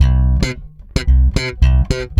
-AL DISCO C#.wav